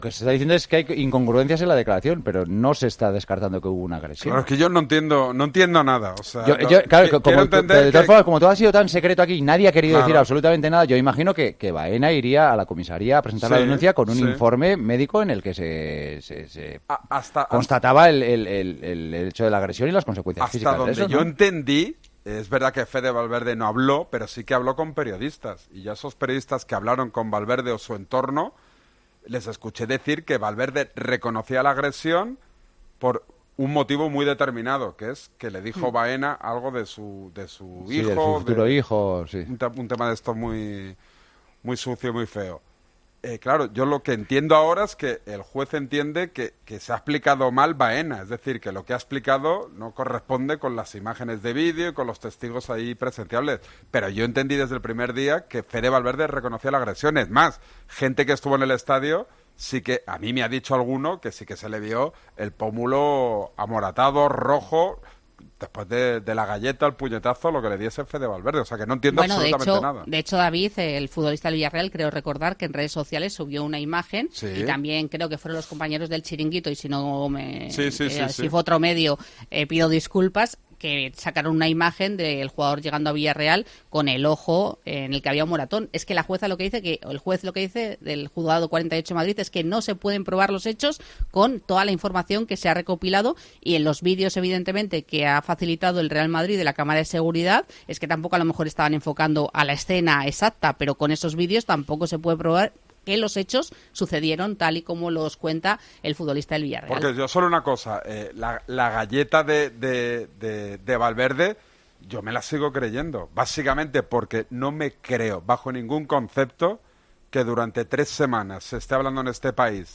AUDIO: Los tertulianos de El Partidazo de COPE dan su opinión sobre la decisión de la jueza de archivar la denuncia del jugador del Villarreal.